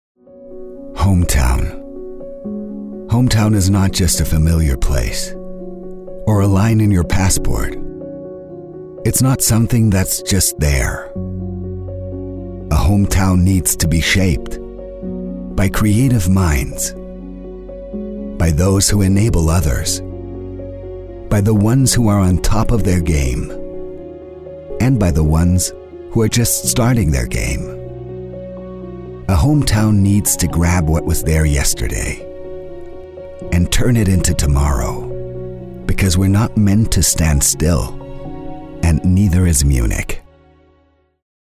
Dank seiner angenehmen sonoren Tonlage kommt er bei den Kunden immer hervorragend an.